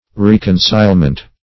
Reconcilement \Rec"on*cile`ment\ (-ment), n.
reconcilement.mp3